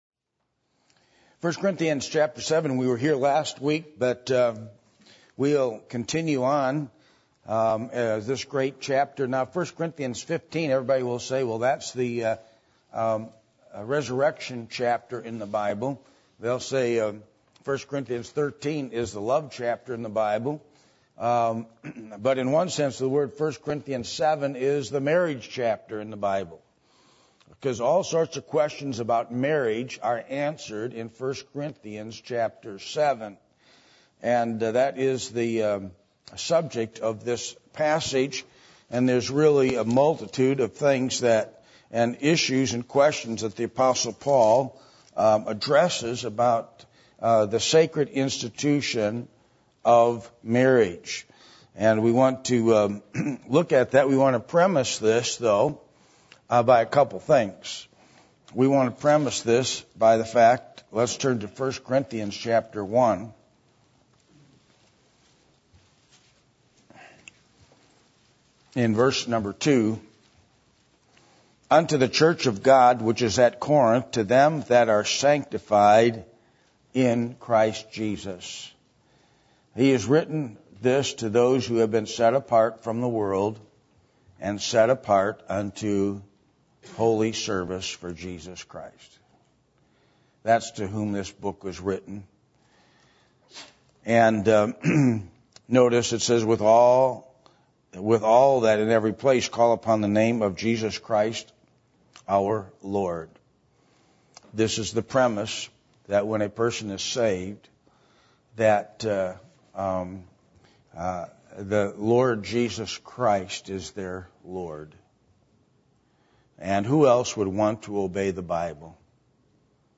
Passage: 1 Corinthians 7:1-20 Service Type: Sunday Morning